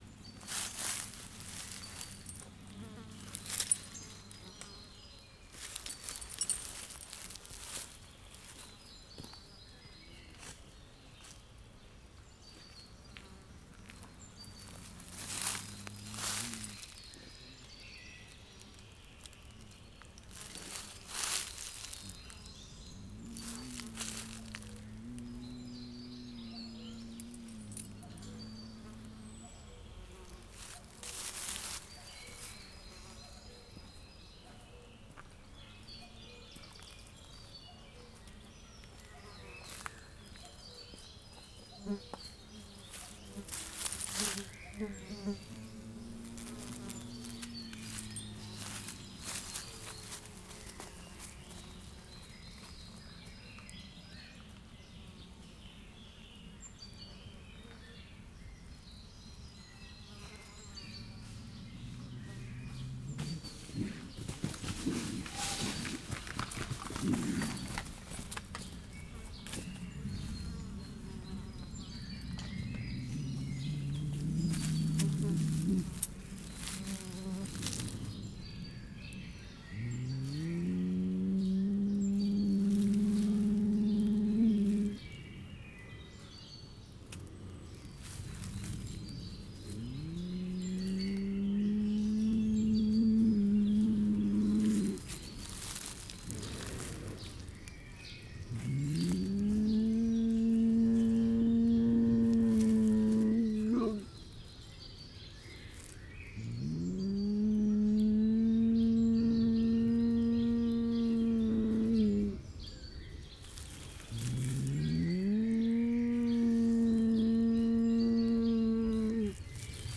Directory Listing of //allathangok/miskolcizoo2018_professzionalis/karpati_hiuz/
morgohang_neszezesazavarban_miskolczoo0717.WAV